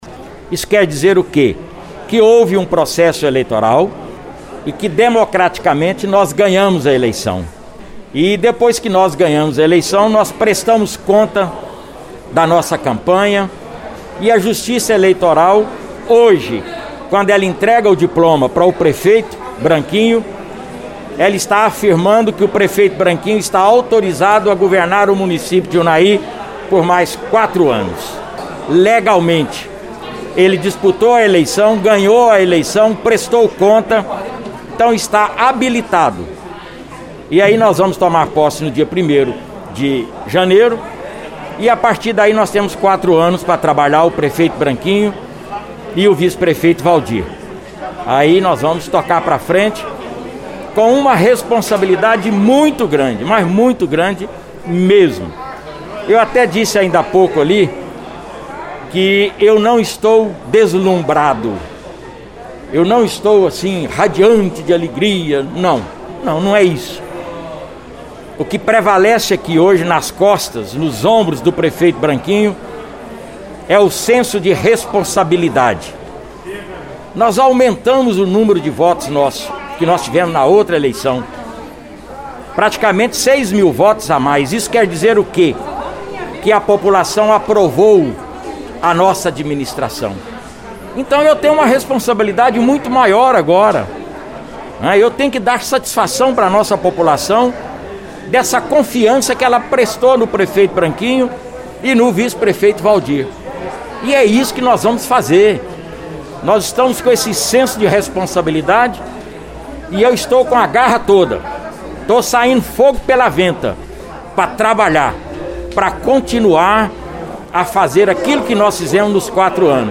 A reportagem da Rádio Veredas ouviu alguns dos personagens deste evento que consolidou a democracia nos dois municípios.
Aos nossos microfones, ele disse que o diploma é a certificação dado pela Justiça Eleitoral de que o mesmo está com as contas da campanha aprovas e apto a governar o município.